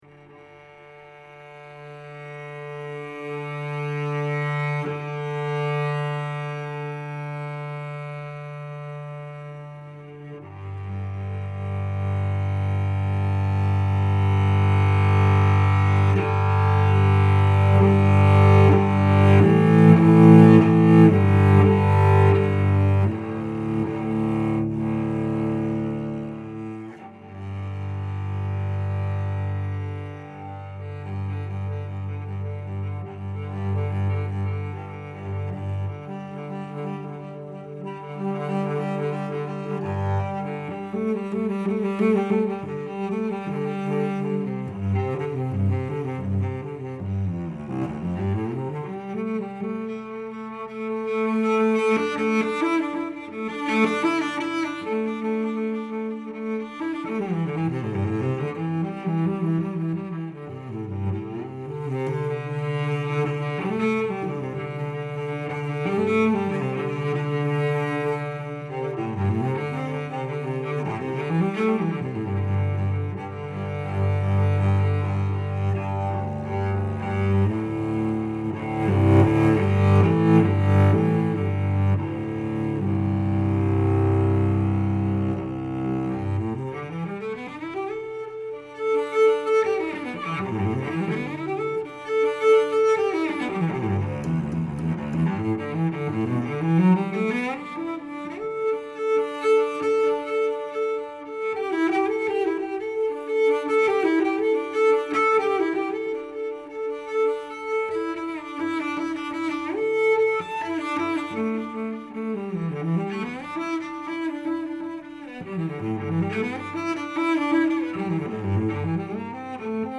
Due to the visual symmetry of the piece, I chose to appropriate open intervals such as the perfect fourth, fifth, and octave as the piece’s principal harmonies.
I used an oscillating gesture throughout the piece to simulate the sheen of the copper and gold leaf in the painting, and I adopted a prevalent three-note motif directly from the first lines on the page.
Cellist